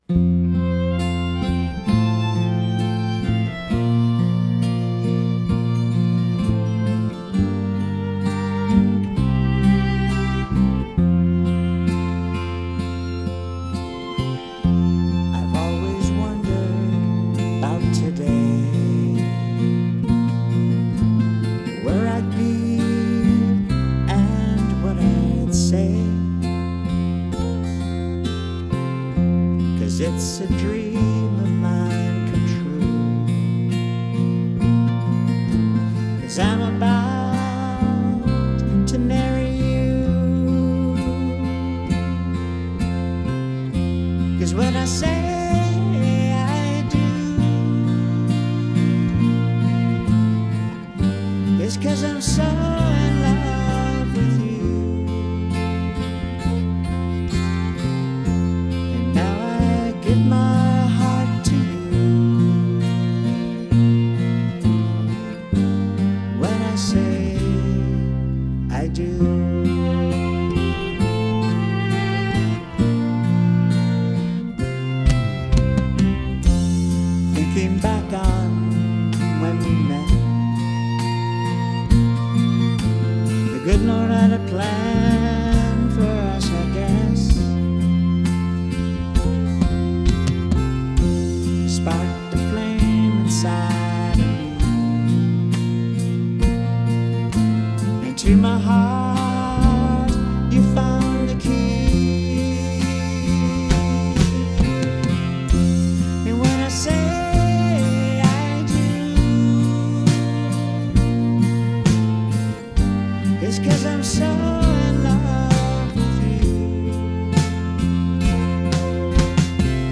Key of F - Track with Reference Vocal